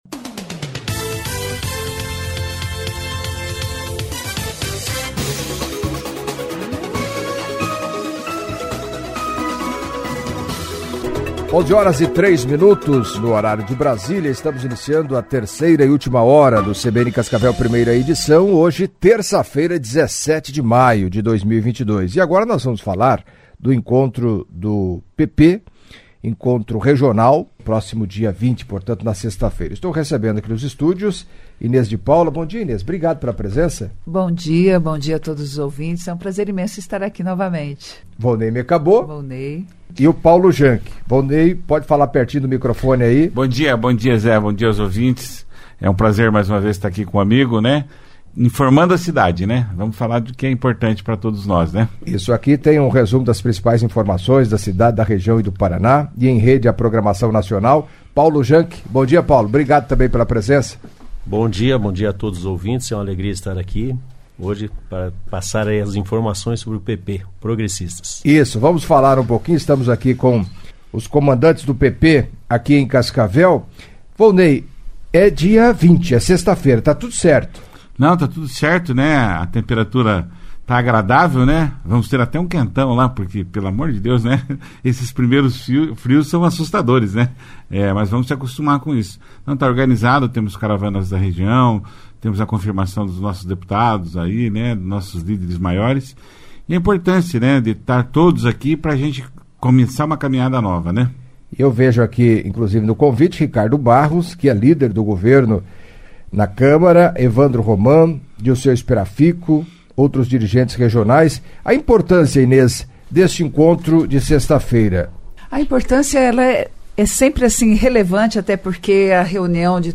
Em entrevista à CBN Cascavel nesta terça-feira (17) membros da executiva municipal do Progressistas falaram, entre outros assuntos, do encontro regional do partido na próxima sexta-feira (20) no salão de eventos do Círculo Italiano de Cascavel, no Parque de Exposições Celso Garcia Cid, às 18h30.